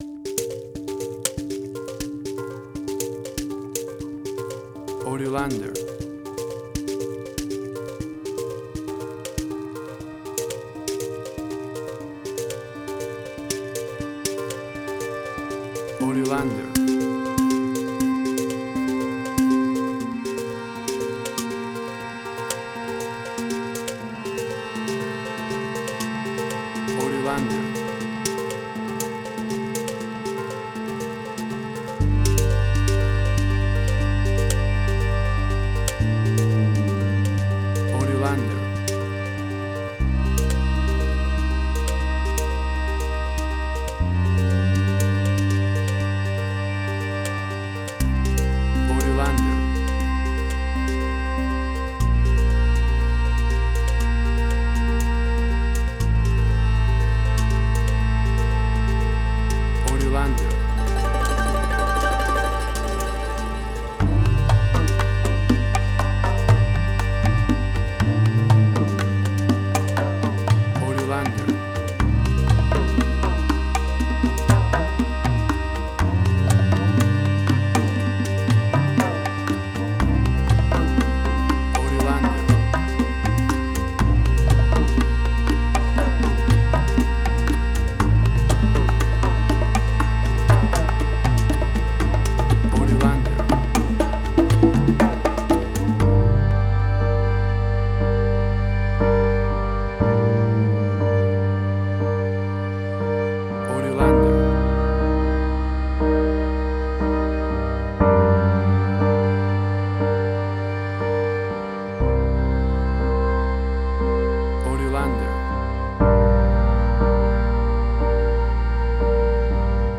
Latin Drama_Similar_Narcos.
WAV Sample Rate: 16-Bit stereo, 44.1 kHz
Tempo (BPM): 60